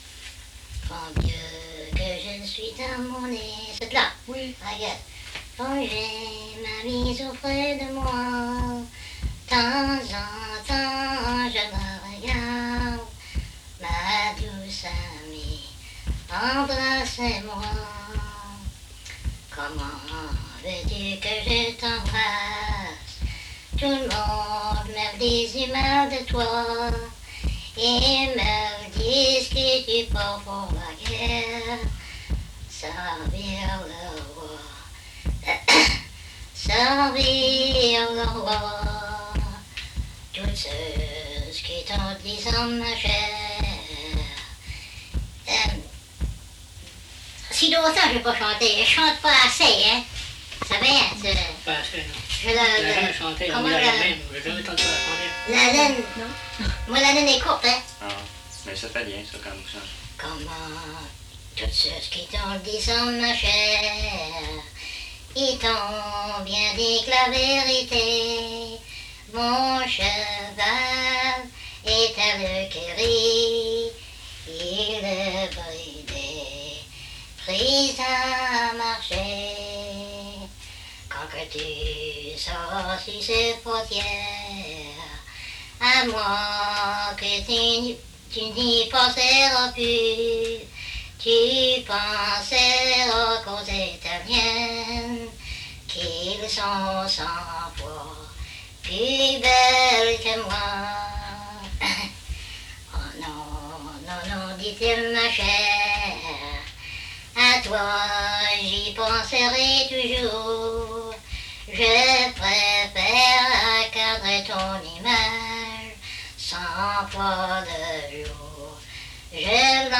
Chanson Item Type Metadata
Emplacement La Grand'Terre